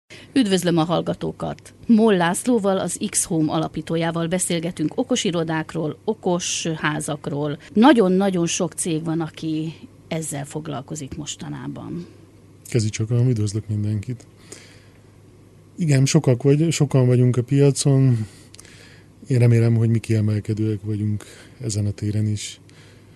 Interjú kezdés Bemutatkozás Mire jó az okosház technológia?
01_Interjú-kezdés.mp3